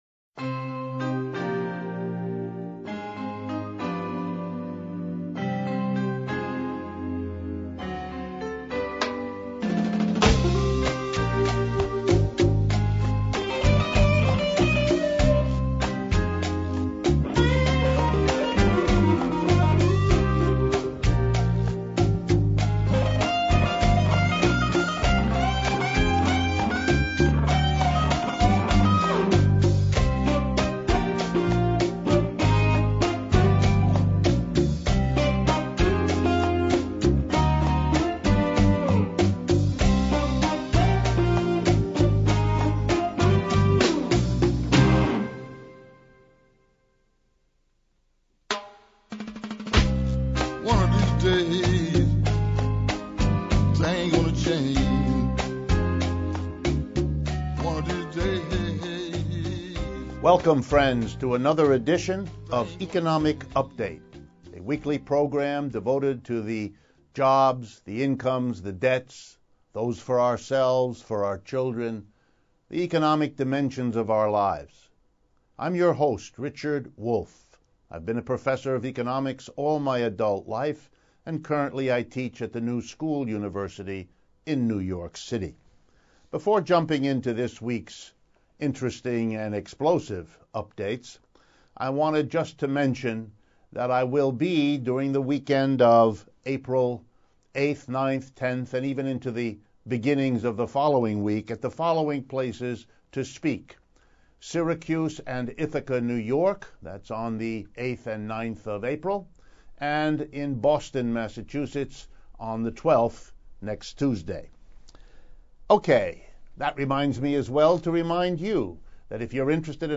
On this week's episode of Economic Update, Prof. Wolff provides updates on tax issues: Panama Papers, corp tax rates, tax-avoiding "inversions" and Yale's tax avoidance. Part 2 of interview